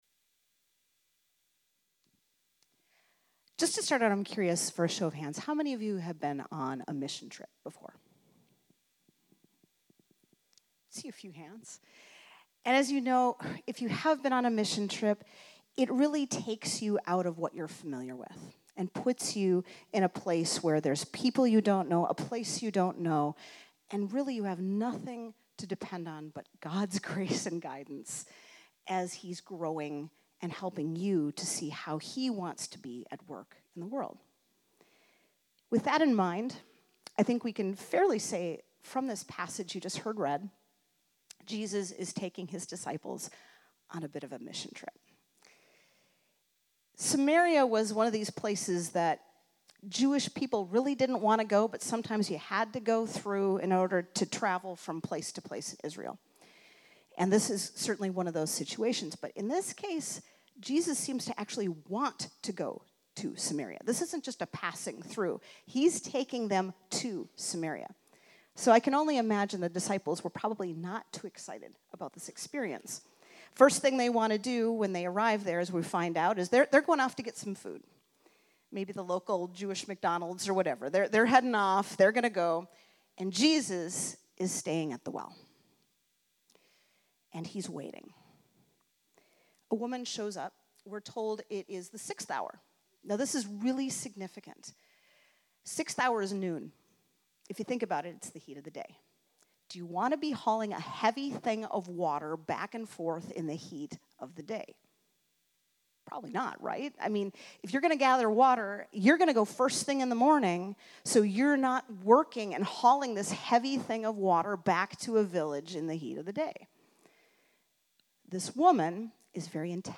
2026 Gospel of John anger fear Jesus Love Sunday Morning Samaritan Woman at the Well Scripture